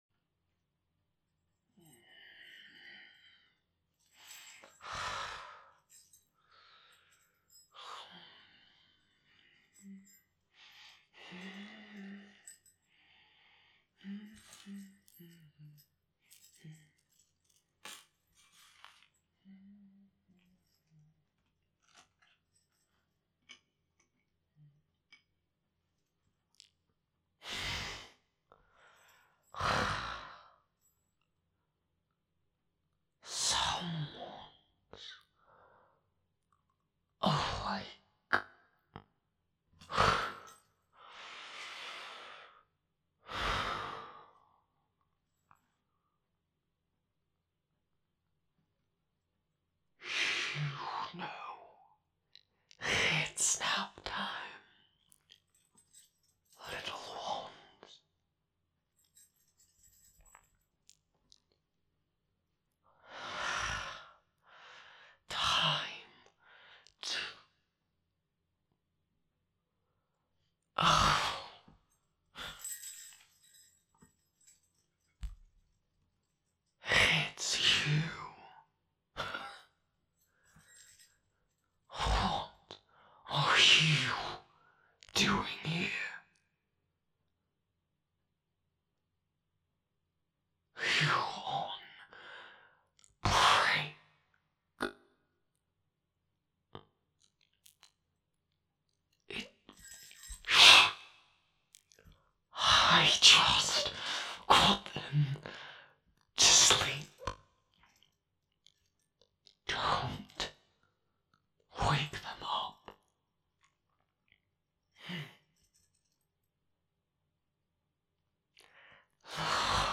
This is the hardest voice I've ever done. I can't growl which makes Moon's voice pretty impossible but I gave it my best try because lots of people keep requesting him.